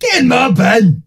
ash_kill_vo_01.ogg